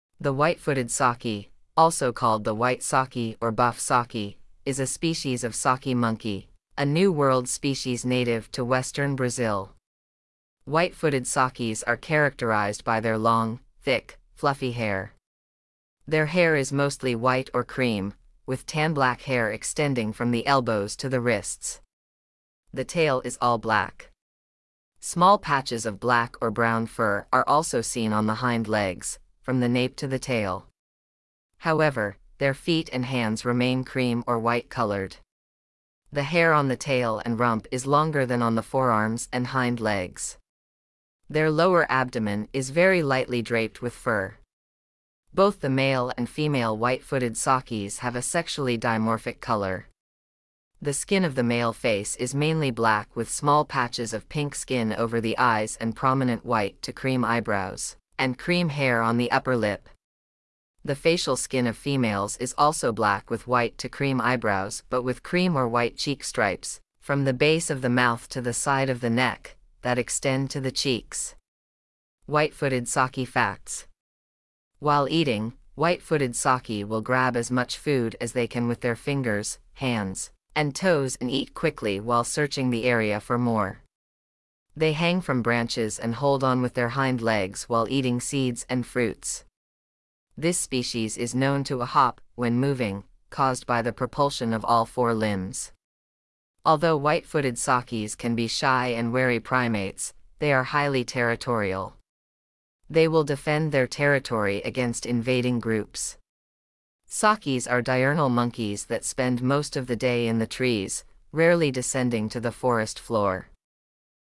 White-footed Saki
White-footed-Saki.mp3